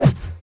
minf_swing1.wav